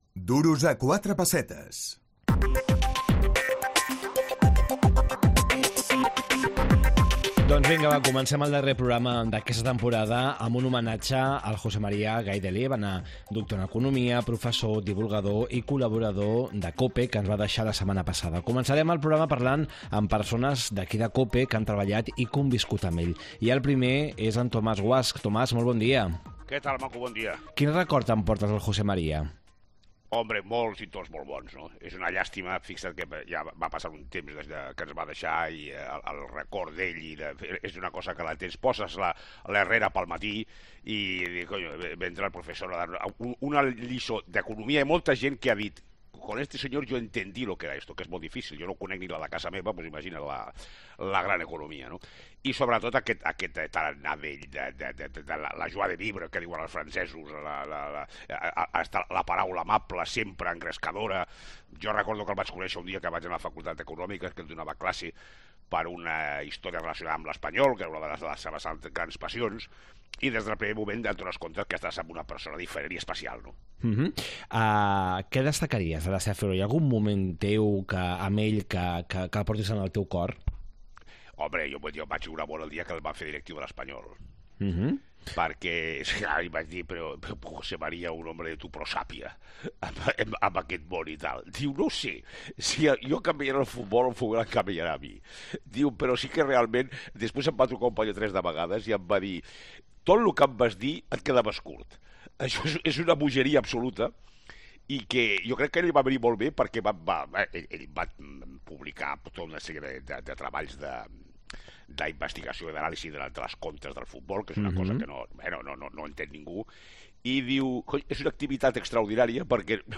Entrevistes